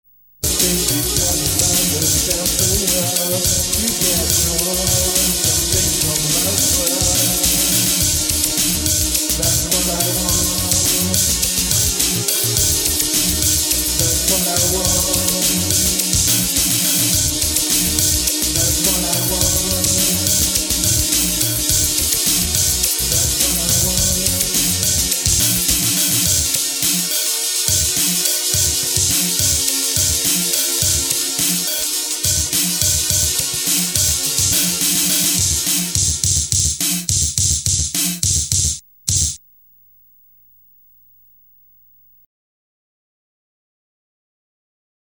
Auch bei diesen Aufnahmen, handelt es sich lediglich nur um Demos und noch hört sich keiner dieser Songs so an, wie sie mir letzten Endes gedanklich vorschwebten, obwohl einige Aufnahmen meinen Vorstellungen bereits recht nahe kommen.